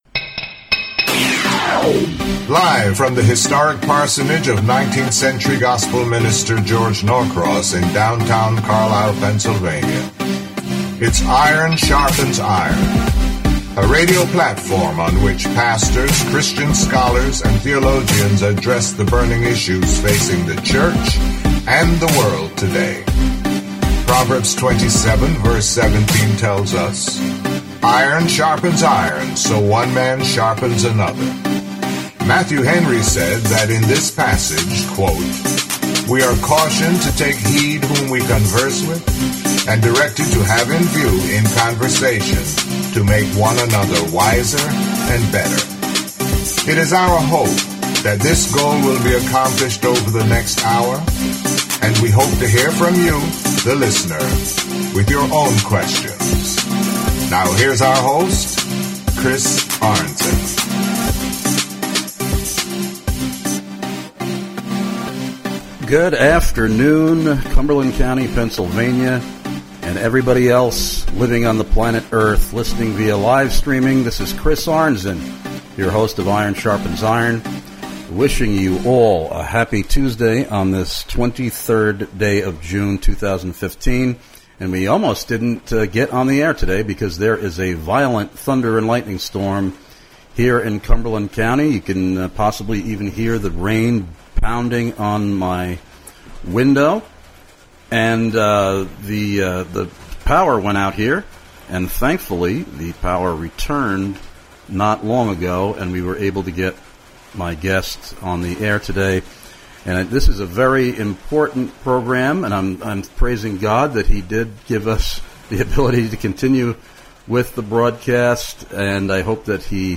Hopefully this interview will help to convey the vital importance of this element of Christian obedience that apparently is grossly neglected, & in fact rarely practiced today within Evangelical Christianity, & that contrary to common misapprehensions this is, in reality, a profound act of Christian *love*.